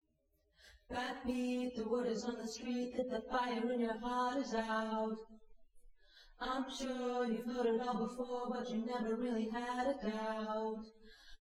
It simply doesn't sound good.
segment after naive Bayes Classification